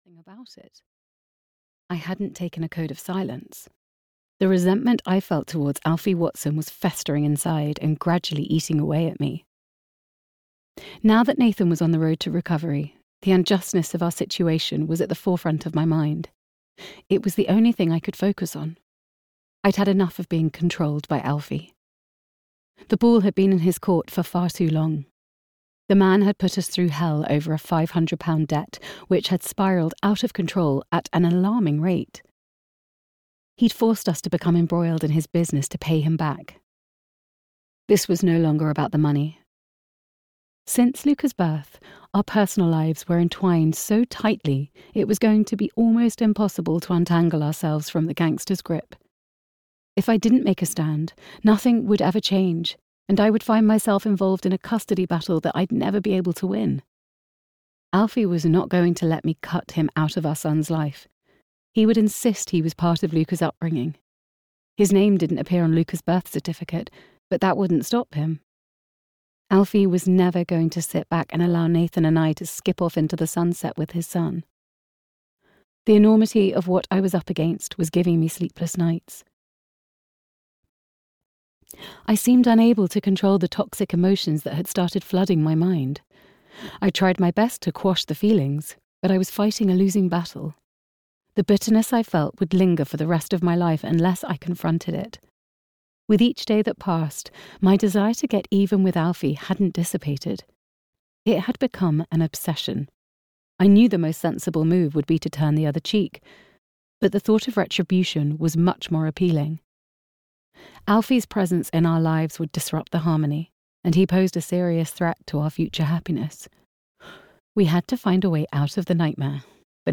Forgive and Forget (EN) audiokniha
Ukázka z knihy